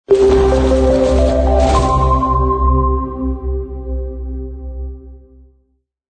(With burning SFX)